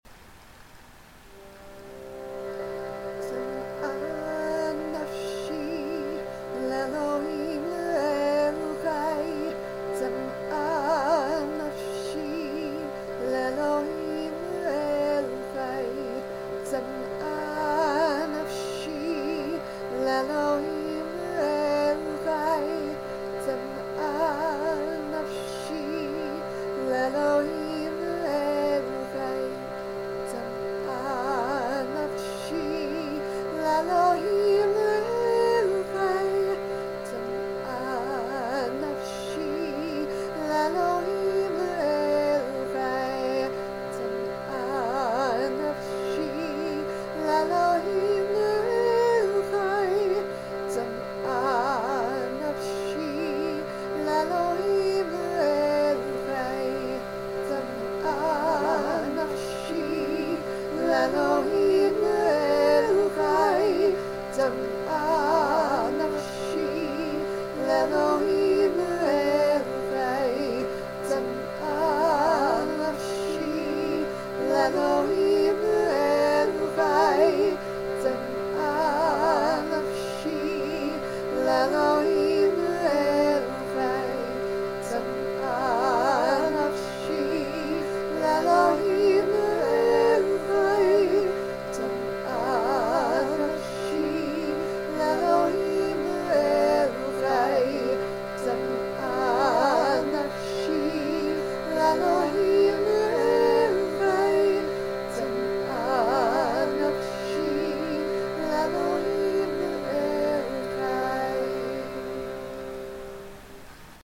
Chants, Psalms